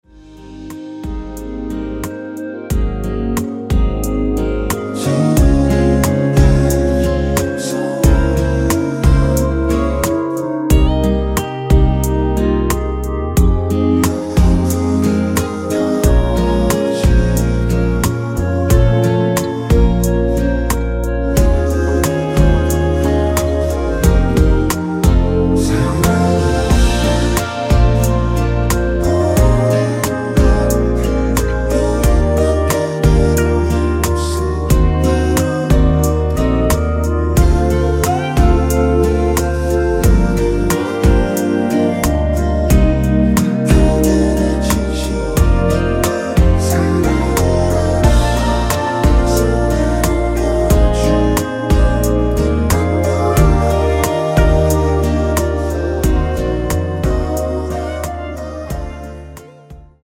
원키에서(-1)내린 멜로디와 코러스 포함된 MR입니다.(미리듣기 확인)
Ab
앞부분30초, 뒷부분30초씩 편집해서 올려 드리고 있습니다.
중간에 음이 끈어지고 다시 나오는 이유는